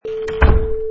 Sound files: Big Door Shut 1
Big door shuts
Product Info: 48k 24bit Stereo
Category: Doors / Doors General
Try preview above (pink tone added for copyright).
Tags: slam , lock , gate , entranceway , doorway
Big_Door_Shut_1.mp3